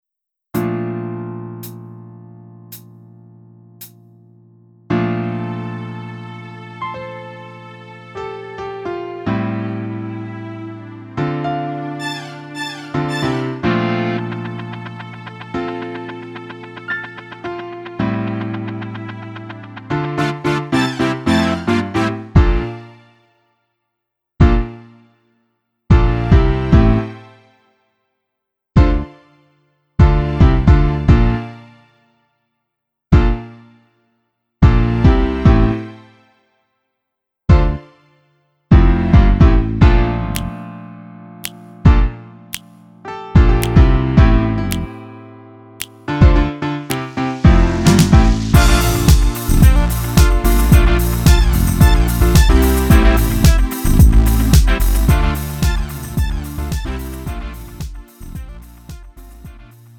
음정 원키 2:51
장르 구분 Lite MR